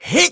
Neraxis-Vox_Jump.wav